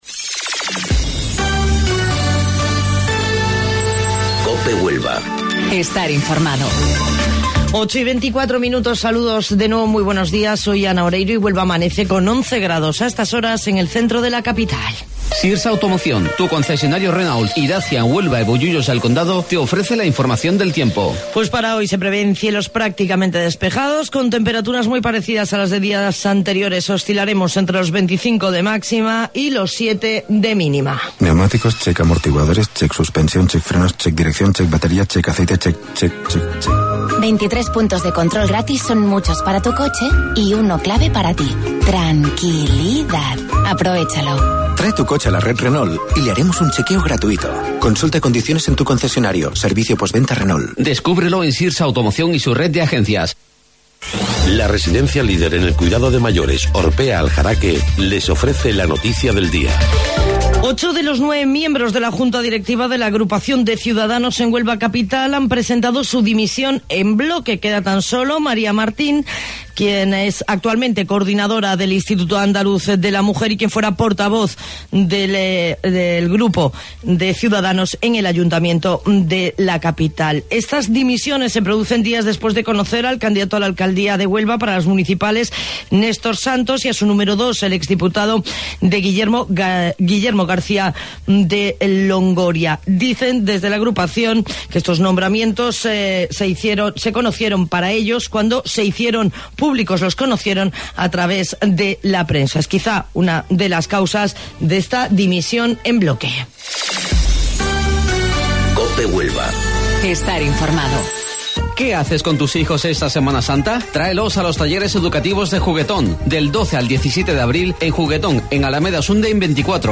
AUDIO: Informativo Local 08:25 del 12 de Abril